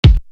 Bumpy Little Kick.wav